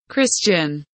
Christian kelimesinin anlamı, resimli anlatımı ve sesli okunuşu